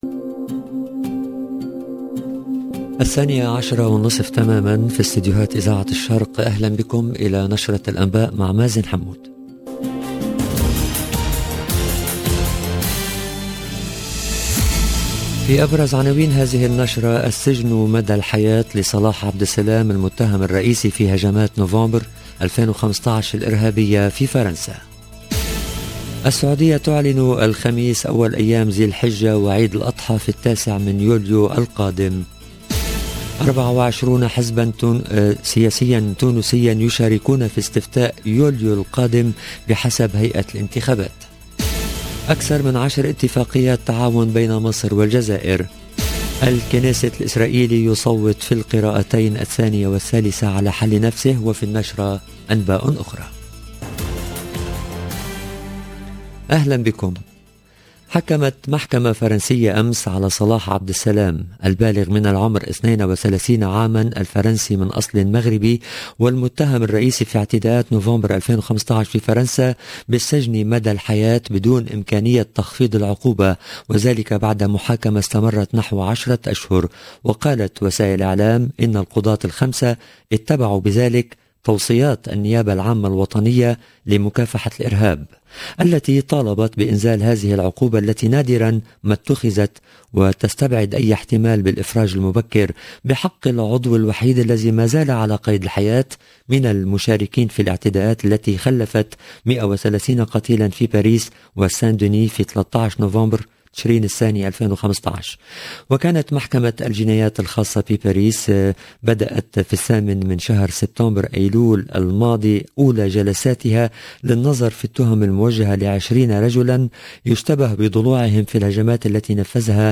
LE JOURNAL EN LANGUE ARABE DE MIDI 30 DU 30/06/22